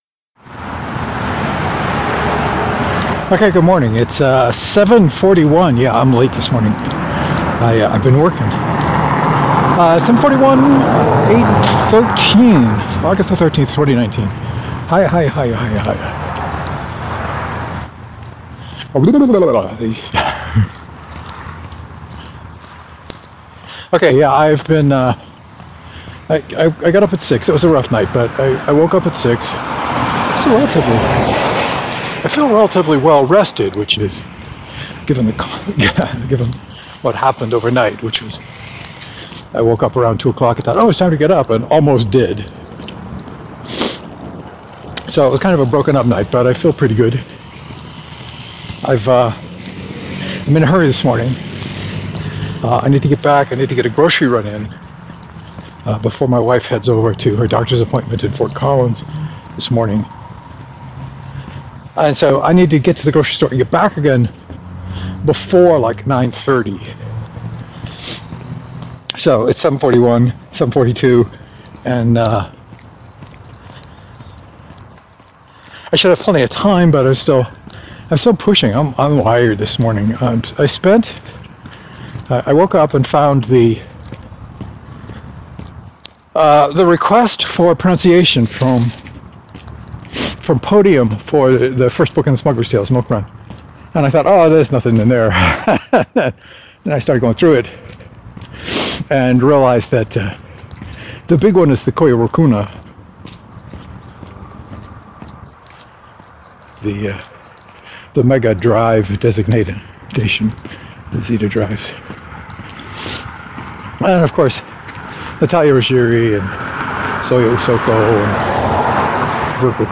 I was talking really fast today for some reason.